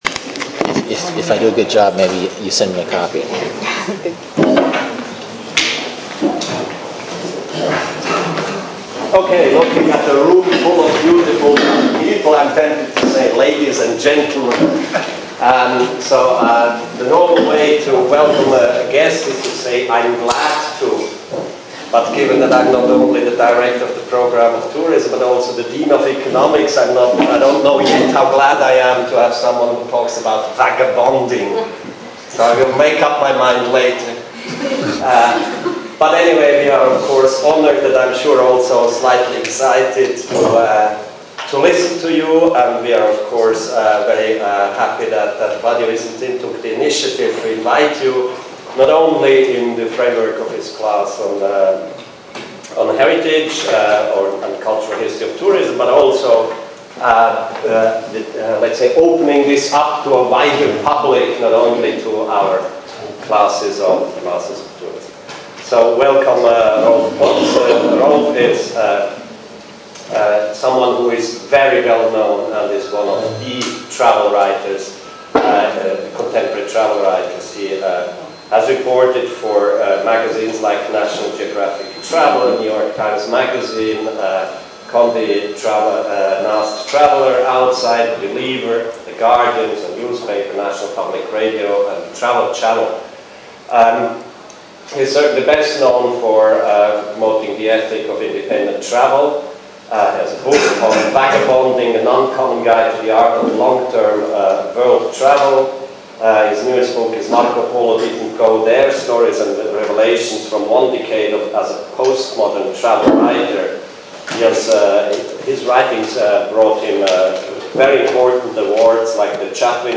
alla festa della Scuola del viaggio